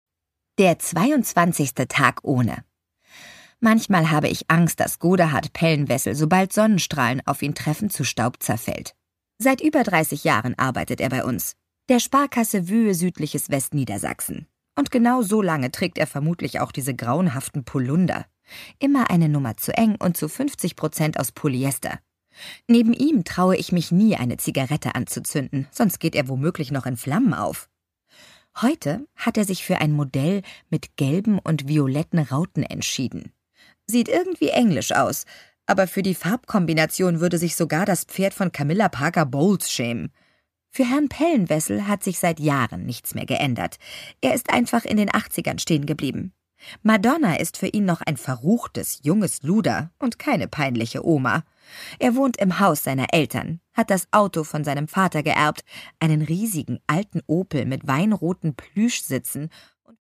Produkttyp: Hörbuch-Download
Fassung: ungekürzt
Gelesen von: Nana Spier